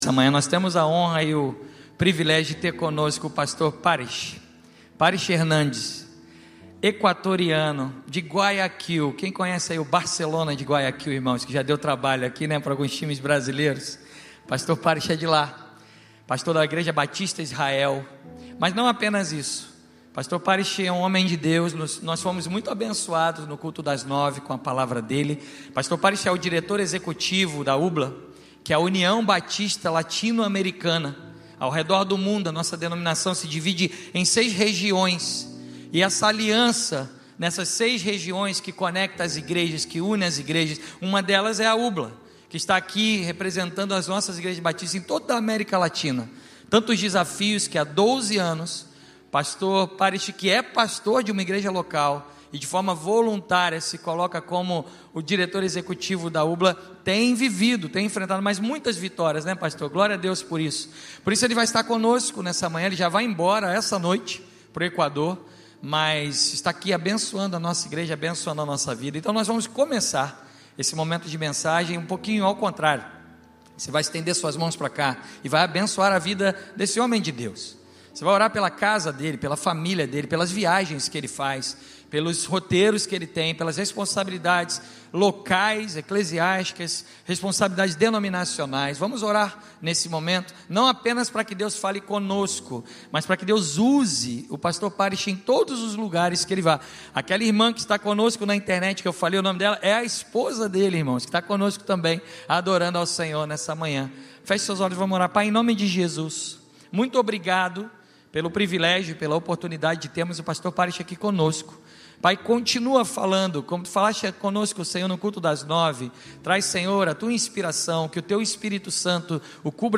Mensagem
na Igreja Batista do Recreio.